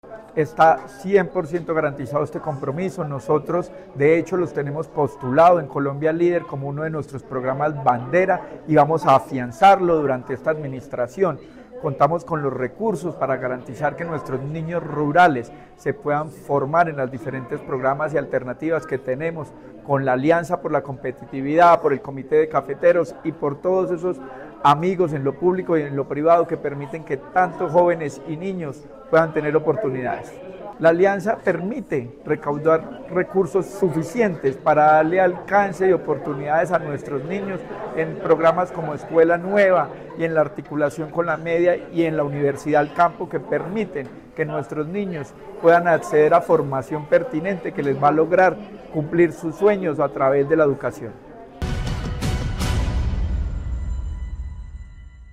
Secretario de Educación de Caldas, Luis Herney Vargas Barrera.